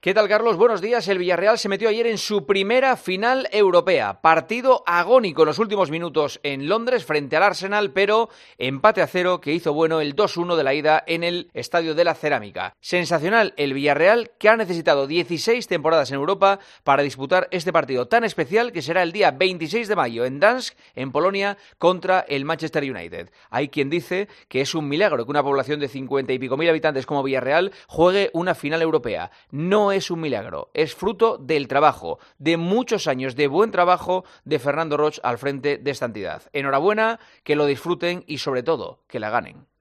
El comentario de Juanma Castaño
El director de 'El Partidazo de COPE' analiza la actualidad deportiva en 'Herrera en COPE'